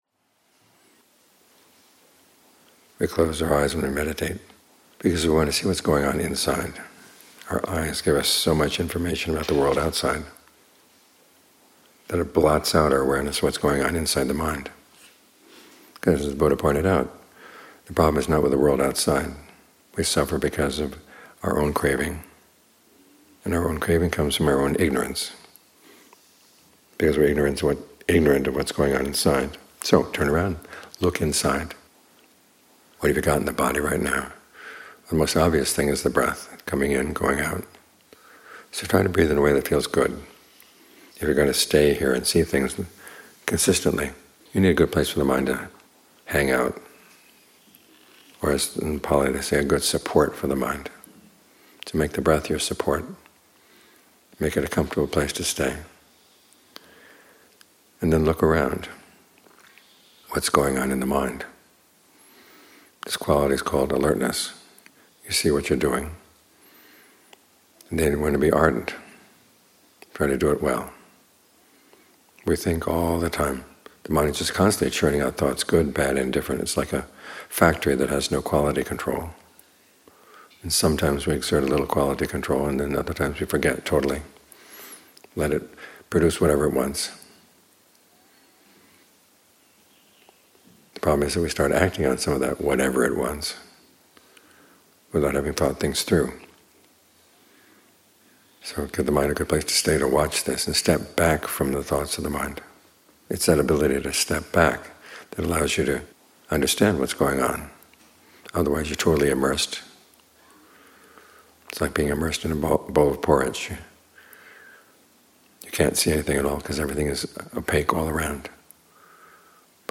Morning Talks (2025)